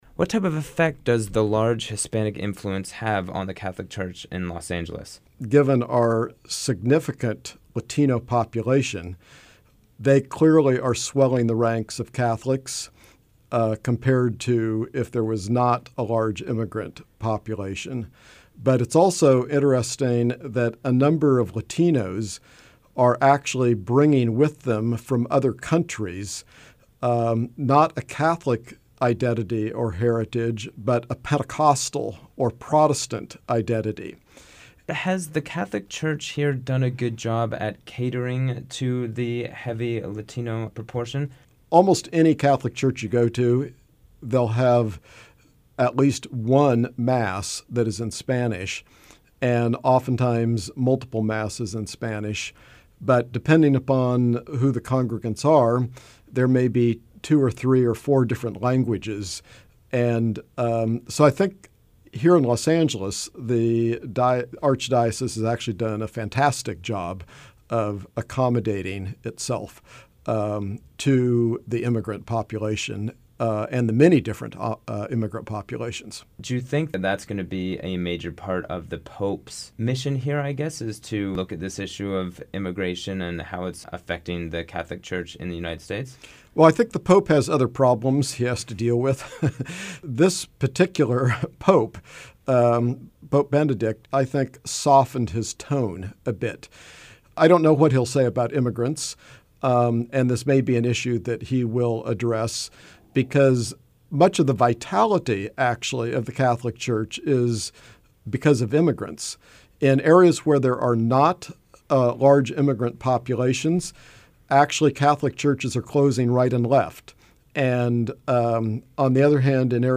hostinterview_417.mp3